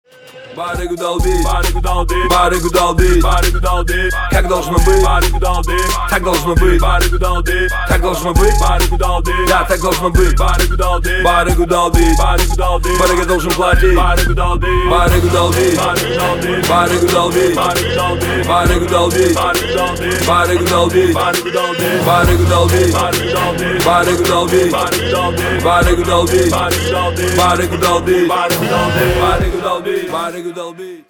Рэп рингтоны